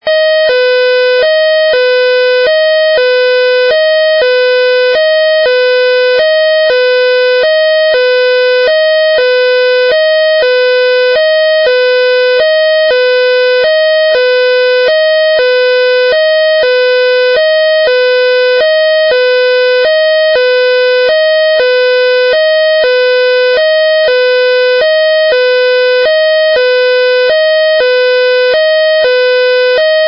警钟声由电子电路生成，声音标准、无噪声 。
慢节拍警报 双音频电话铃